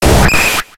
Cri de Kabuto dans Pokémon X et Y.